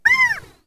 Seagull
Seagull.wav